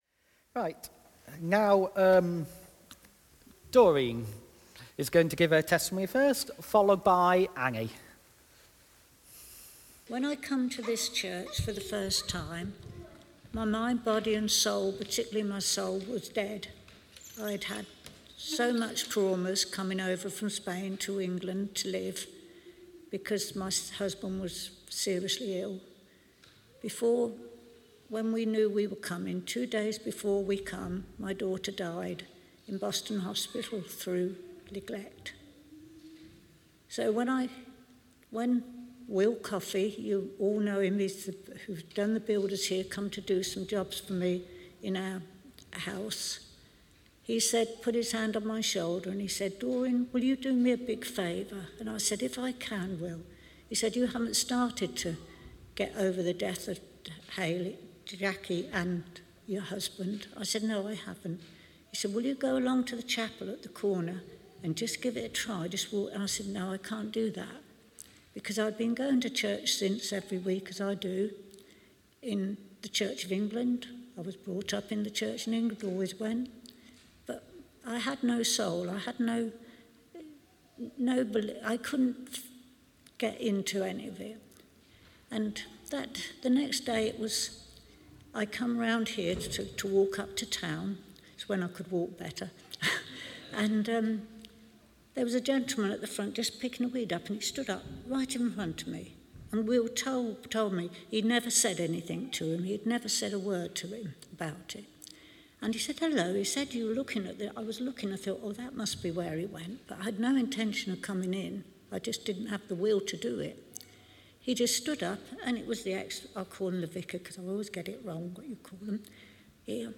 Testimonies 2: more church member witness to God's actions in their lives.